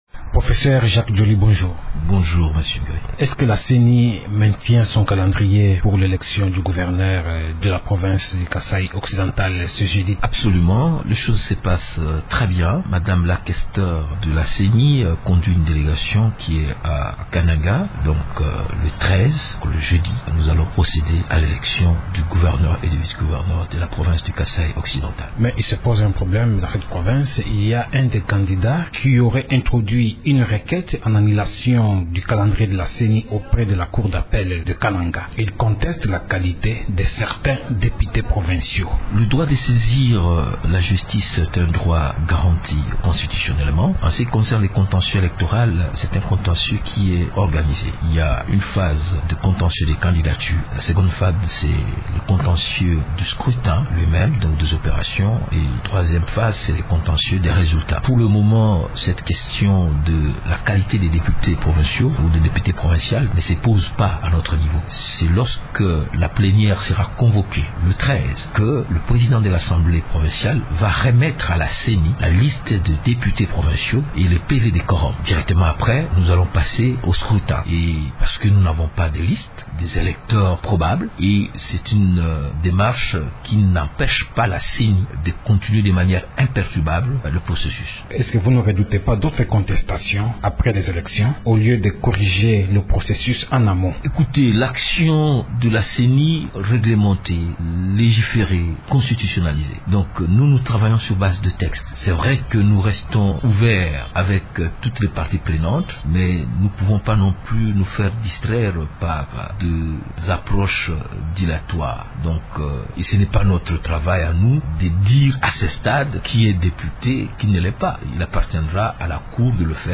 Jacques Djoli parle des élections dans ces deux provinces et confirme qu’elle va organiser ce jeudi au Kasaï Occidental.